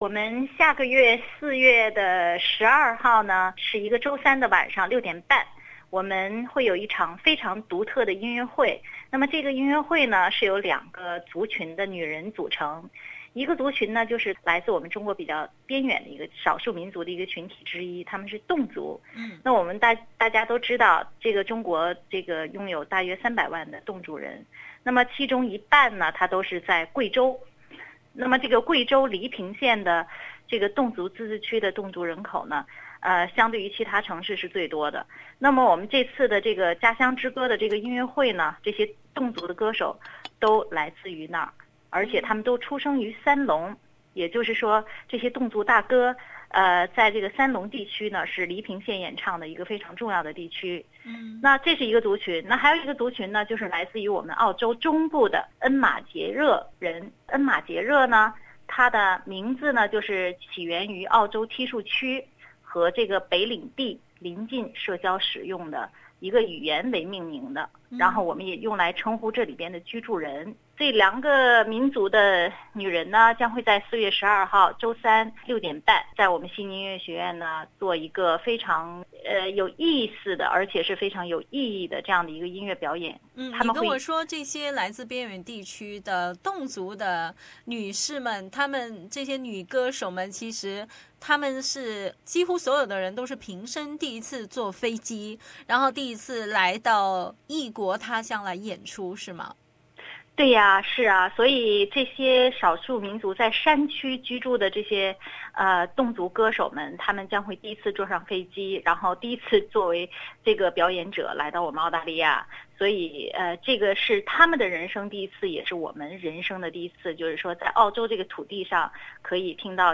侗族大歌的表演者们来到SBS，提前感受下他们独特的表演吧：